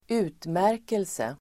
Ladda ner uttalet
Uttal: [²'u:tmär:kelse]